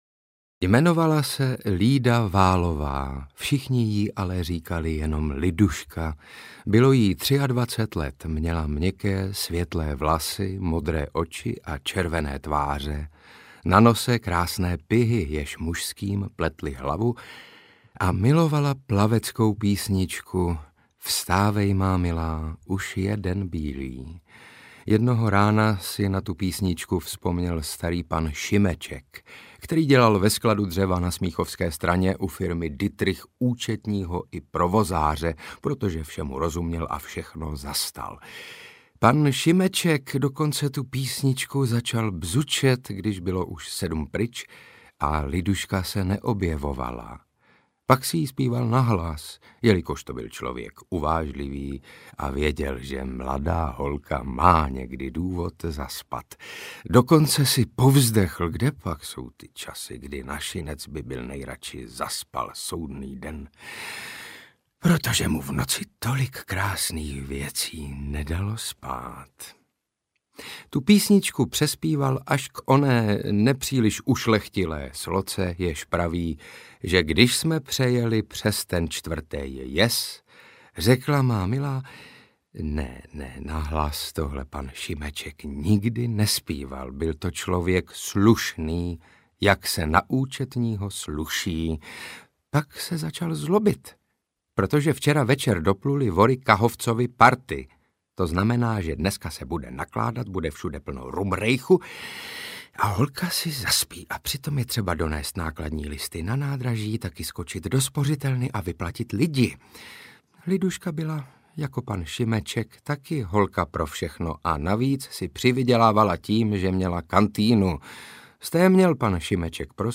Panoptikum města pražského audiokniha
Ukázka z knihy
Hudba Karpof Brothers
Natočeno ve studiu KARPOFON (AudioStory) v roce 2025
• InterpretJaromír Meduna, Otakar Brousek ml., Saša Rašilov ml.